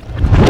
VEC3 Reverse FX
VEC3 FX Reverse 13.wav